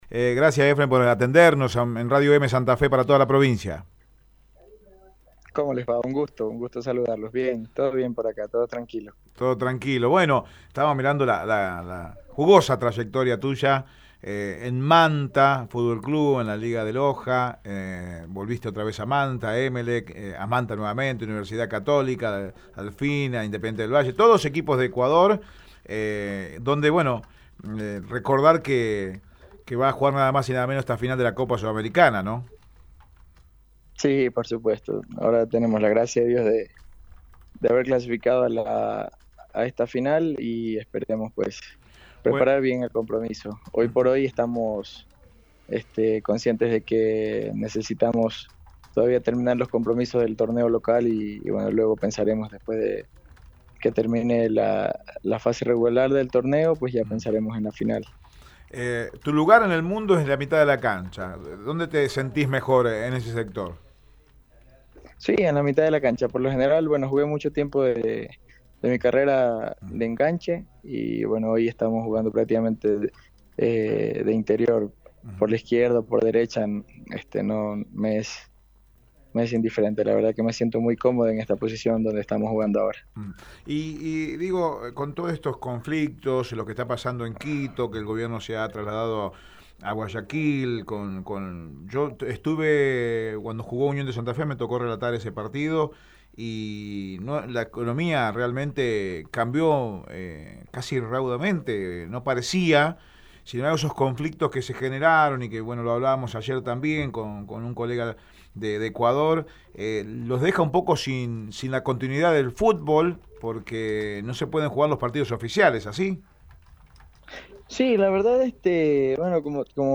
En diálogo con Radio Eme Deportivo